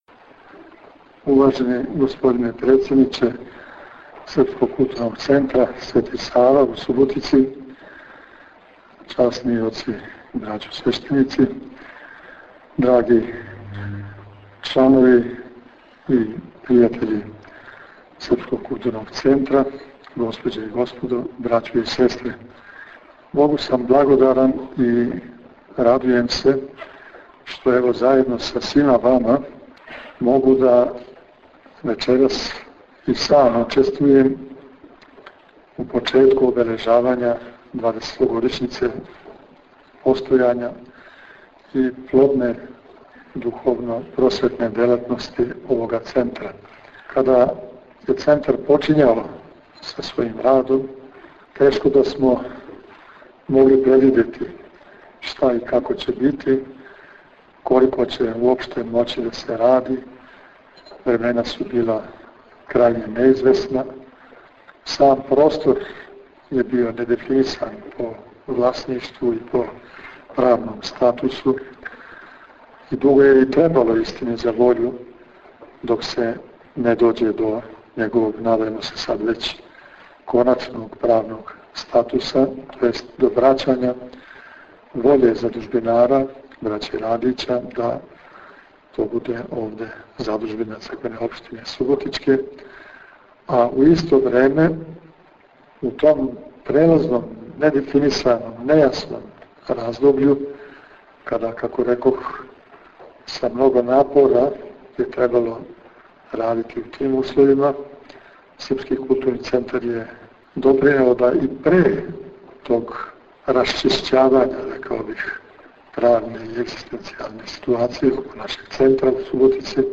Свечаност у сали Задужбине Душана Радића, у присуству свештенства суботичког намесништва и великог броја Суботичана, беседом је отворио Његово Преосвештенство Епископ бачки Господин др Иринеј.